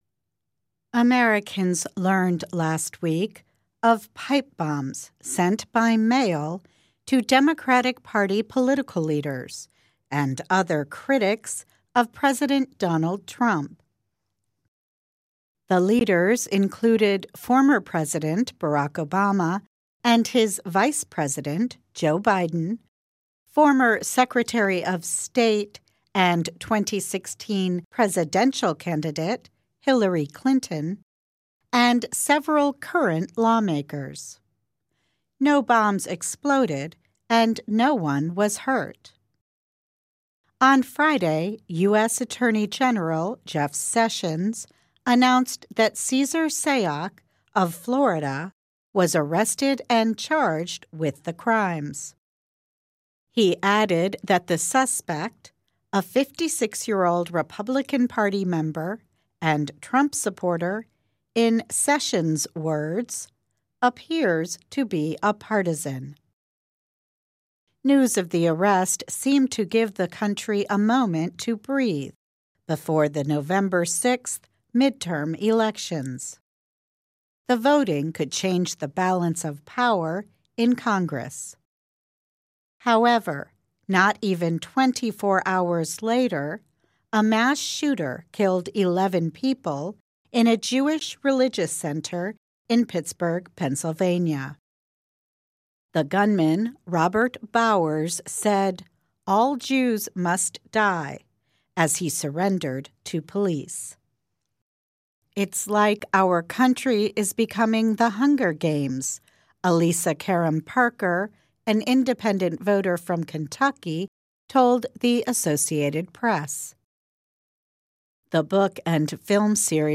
慢速英语:美国人在暴力与愤怒中投票